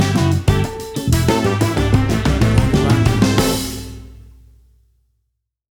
WAV Sample Rate: 16-Bit stereo, 44.1 kHz
Tempo (BPM): 93